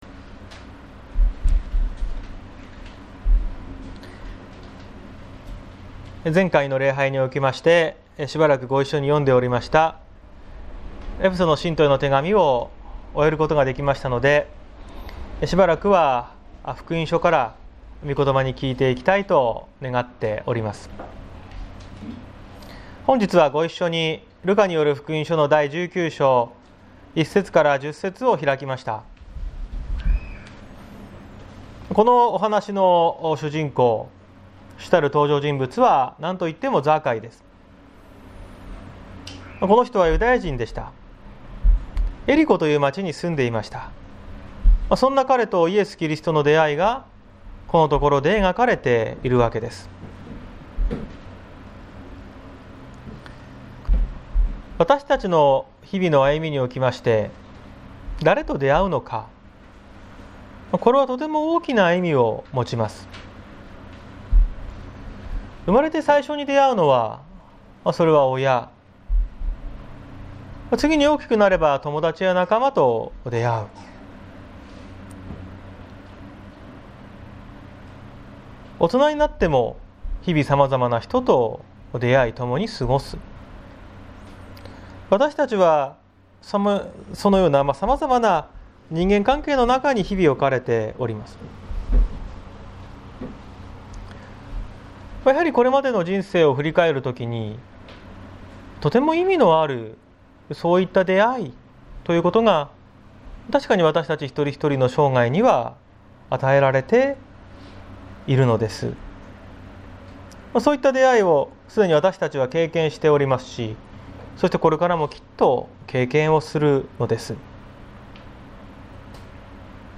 2021年06月06日朝の礼拝「あなたを救うために」綱島教会
綱島教会。説教アーカイブ。